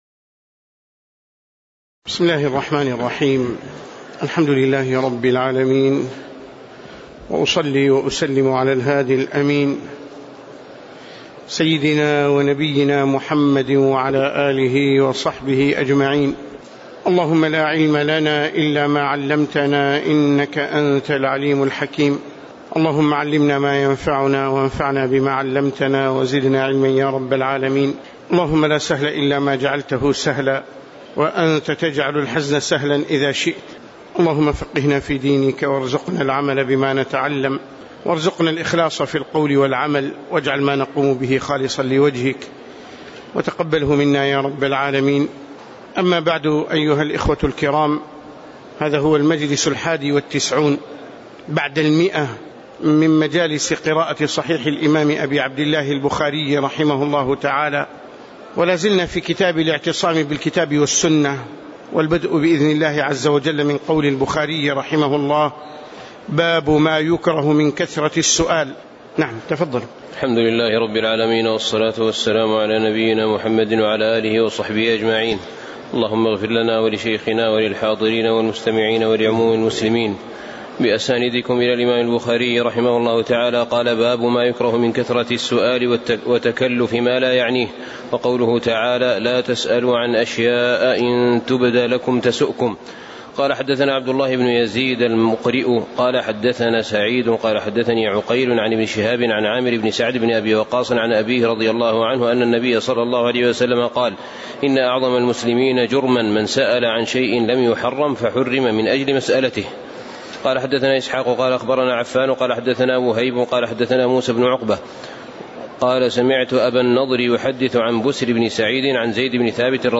تاريخ النشر ٥ جمادى الأولى ١٤٣٩ هـ المكان: المسجد النبوي الشيخ